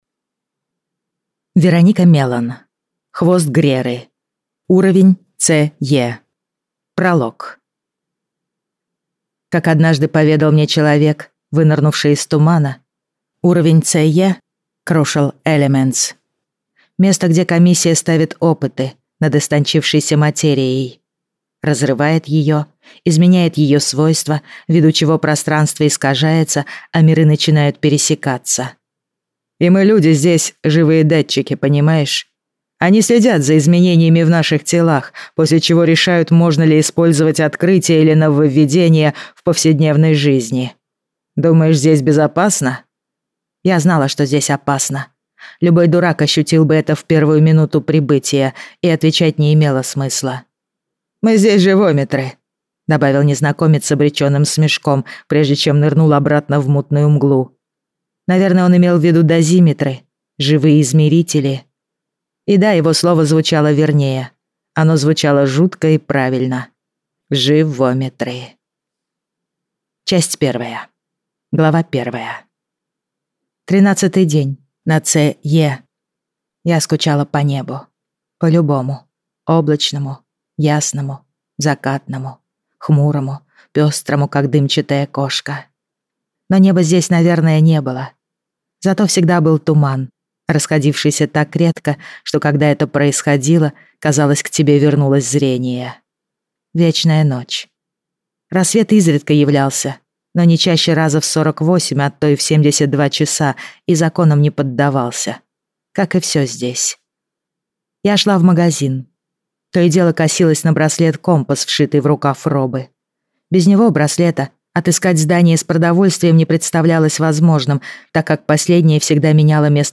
Аудиокнига Хвост Греры | Библиотека аудиокниг